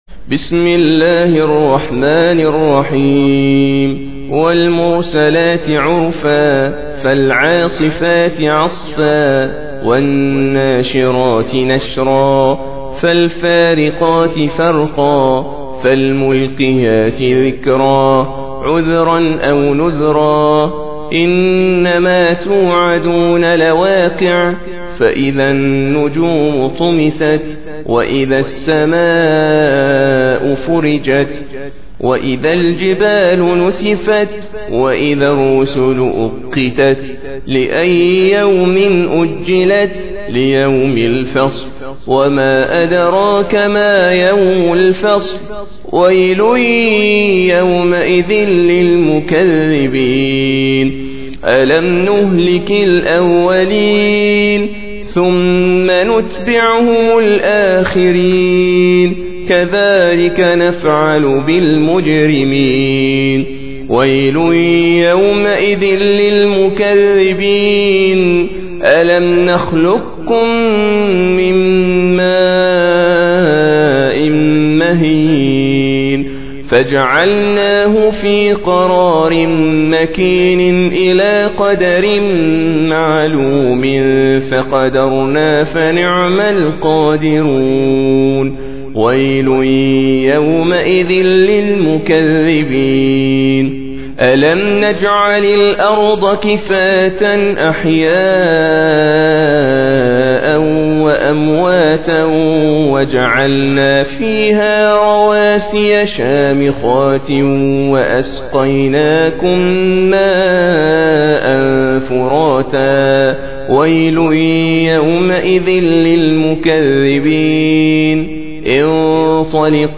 Surah Sequence تتابع السورة Download Surah حمّل السورة Reciting Murattalah Audio for 77.